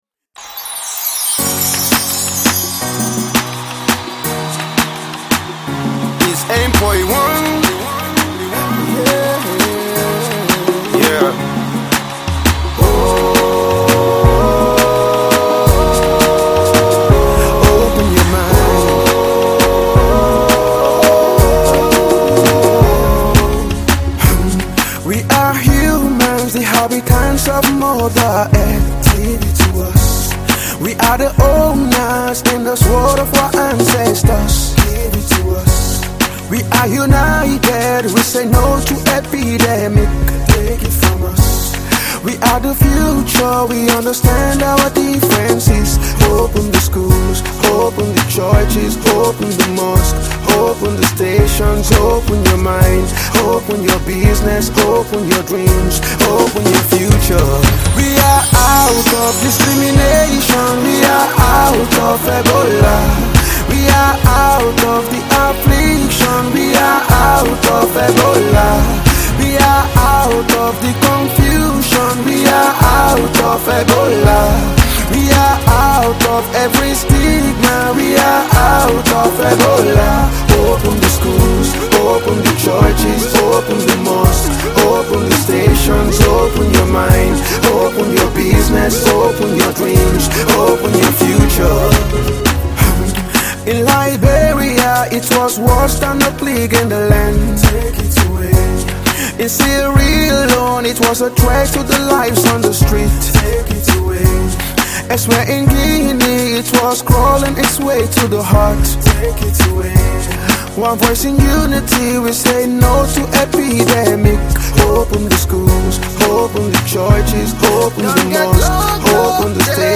A Gospel Artist